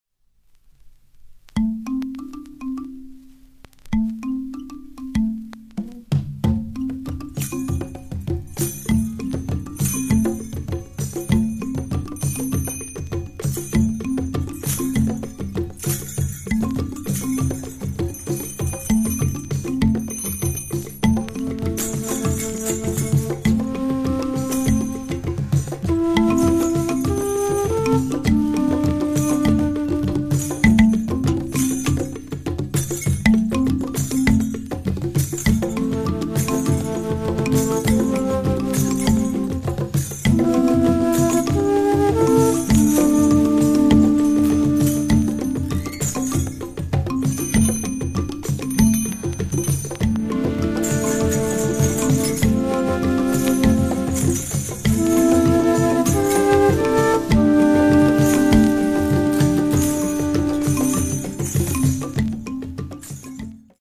(注) 原盤の状態によりノイズが入っています。